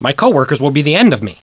Mr Rude's voice is booming!